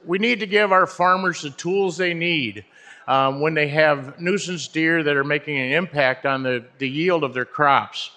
State Senator Patrick Joyce spoke on the Senate floor Thursday.